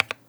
key-press-4.wav